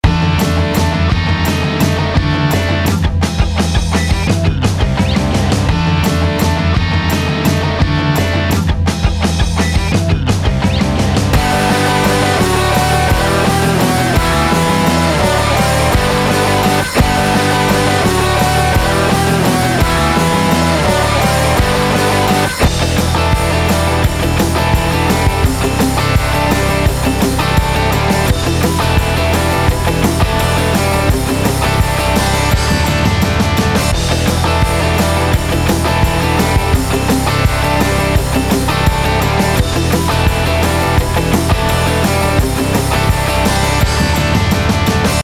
Nun mit dem Drawmer 1973: